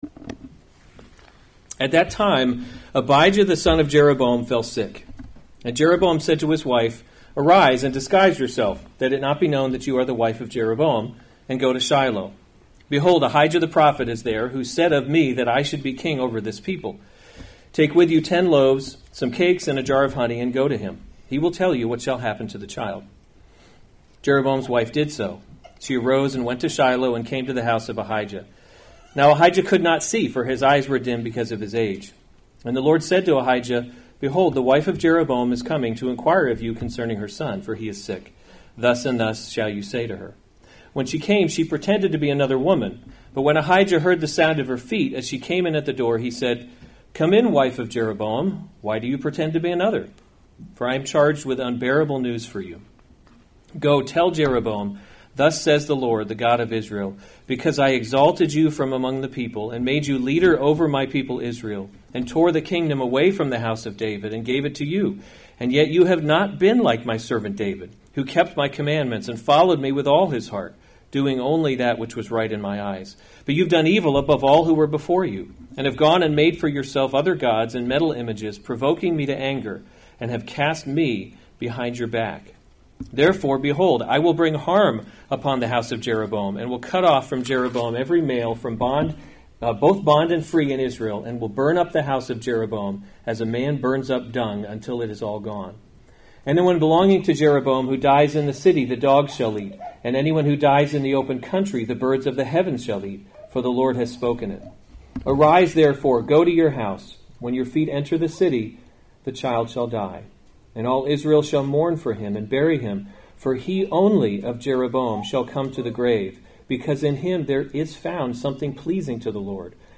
March 16, 2019 1 Kings – Leadership in a Broken World series Weekly Sunday Service Save/Download this sermon 1 Kings 14:1-20 Other sermons from 1 Kings Prophecy Against Jeroboam 14:1 At […]